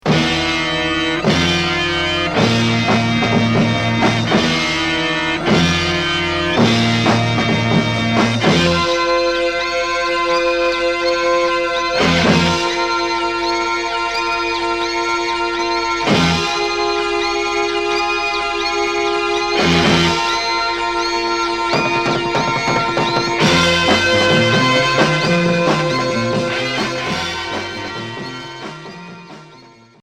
Progressif